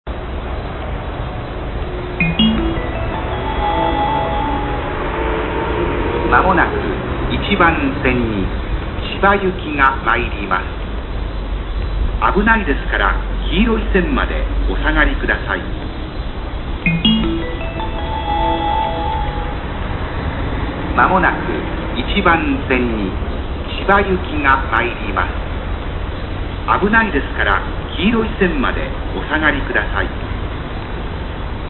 接近放送千葉行きA線千葉行きの接近放送です。法規上では東海道線の緩行線ため、種別が普通しかないため種別を言いません。